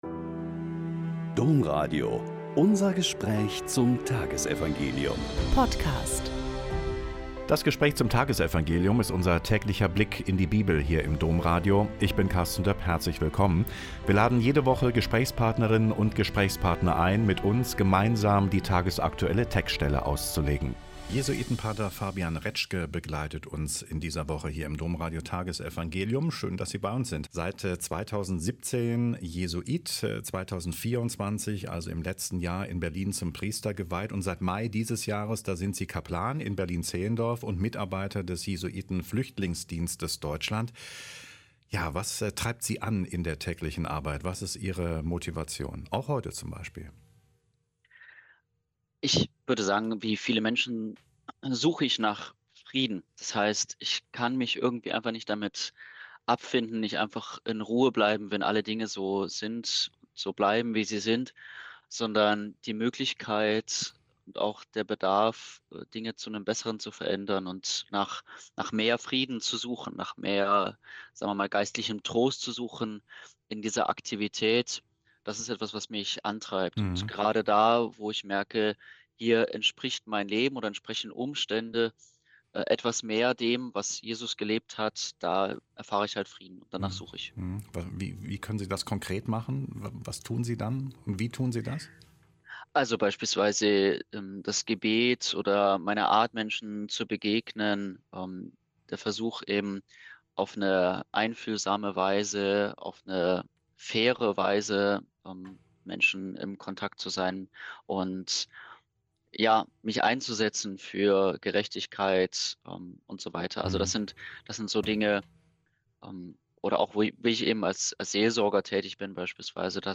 Lk 9,51-56 - Gespräch